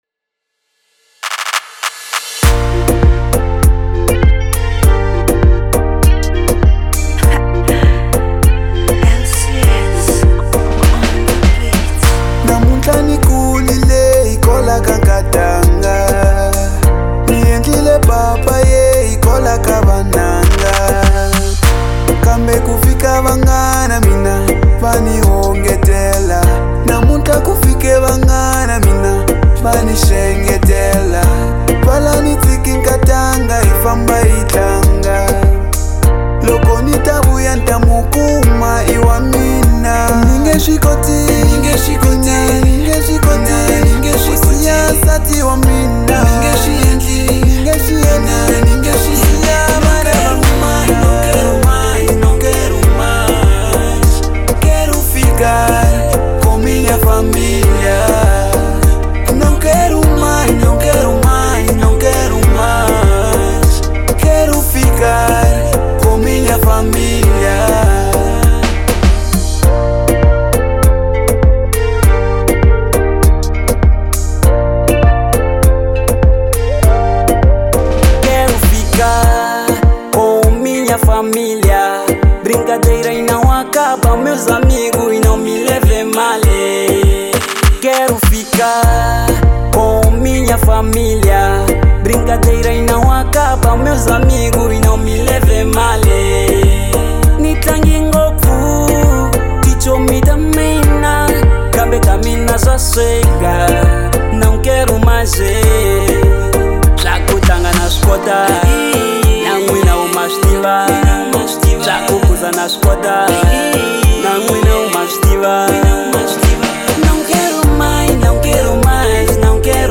| Afro romance